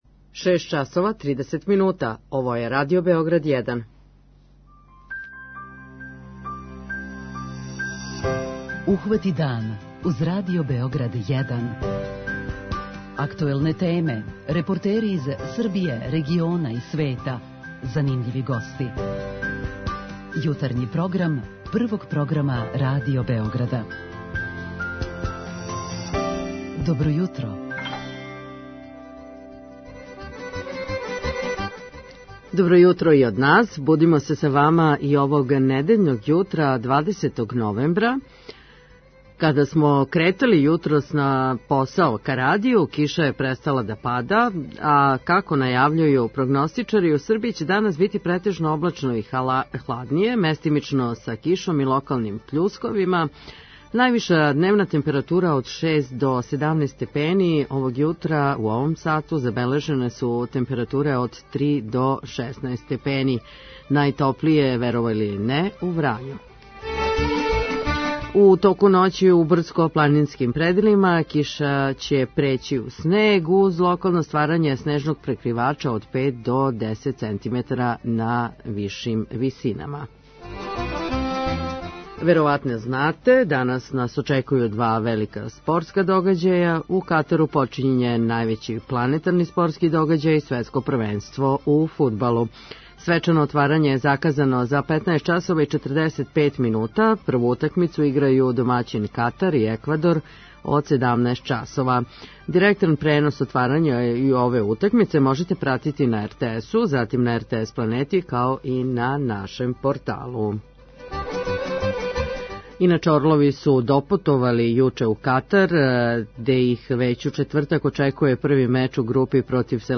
16.20 MB Ухвати дан Autor: Група аутора Јутарњи програм Радио Београда 1!